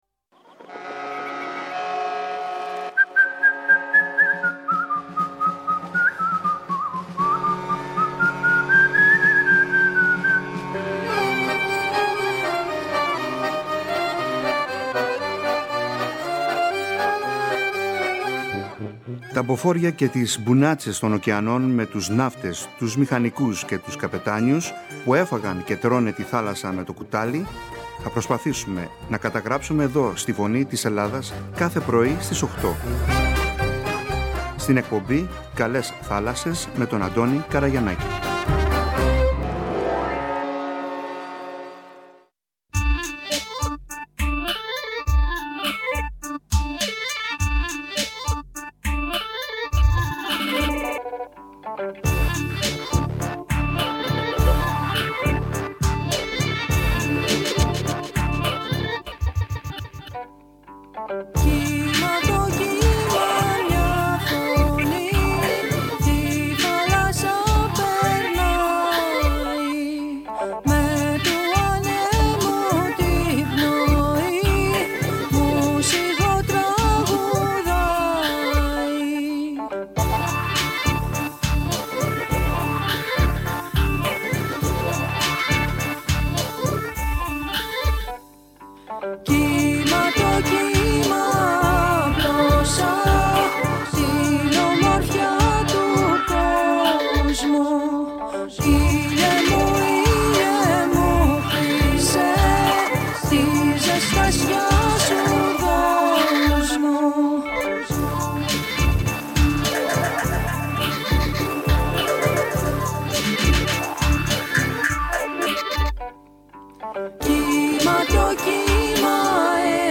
Μια ενδιαφέρουσα συνέντευξη- κουβέντα